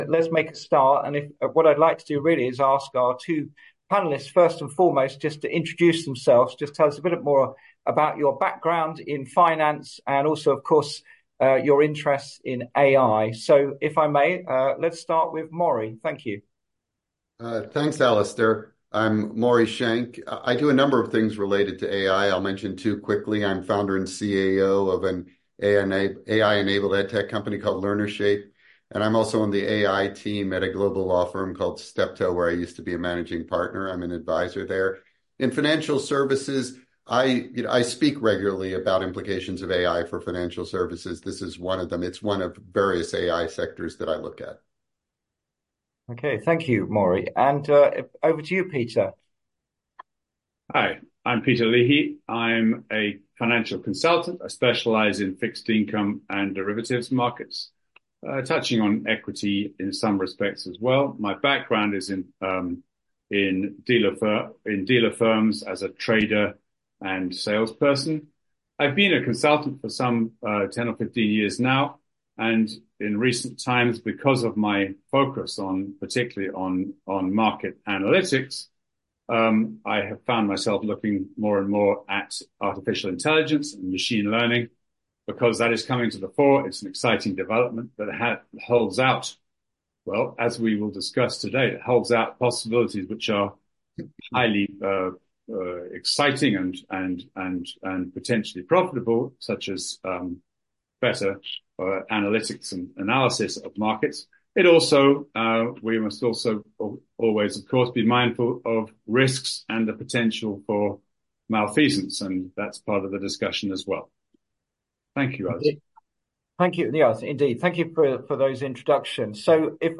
Watch, listen, or read our fireside discussion on the role of AI in financial markets. Industry experts give their unique take.